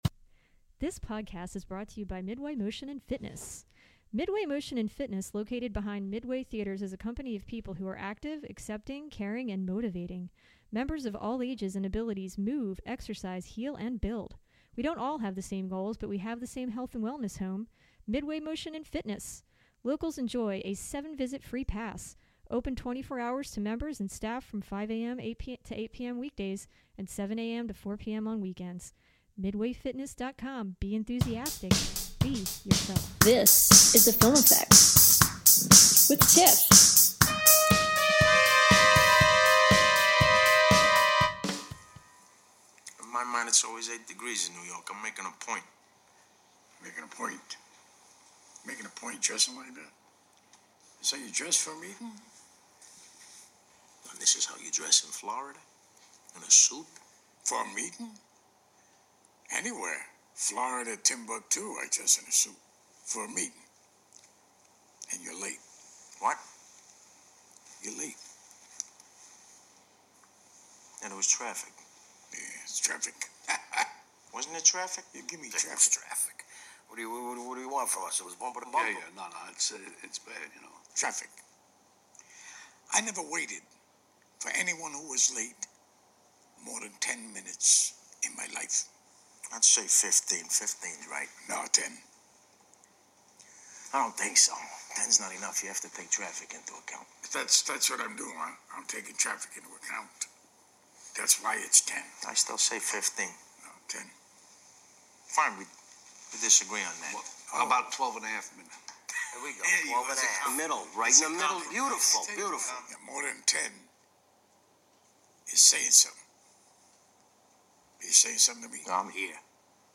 See if you can actually follow our conversation as we try not to talk over each other and argue like only family can.